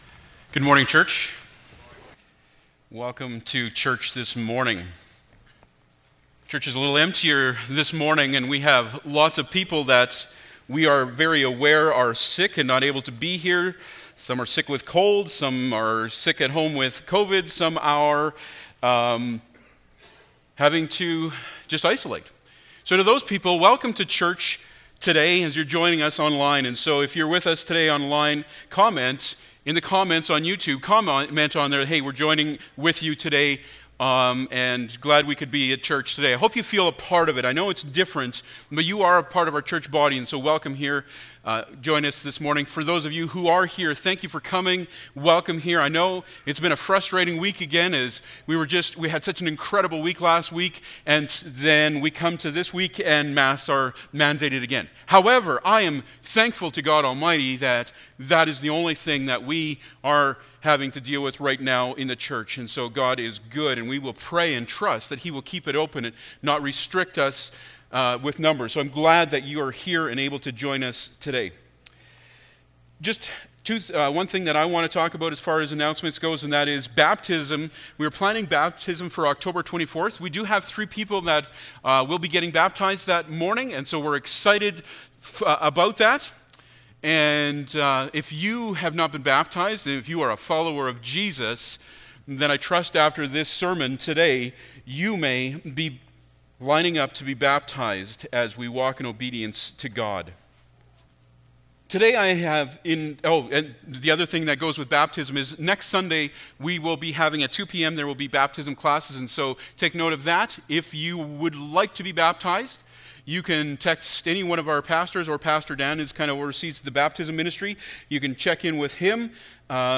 Jeremiah 32:6-10 Service Type: Sunday Morning Bible Text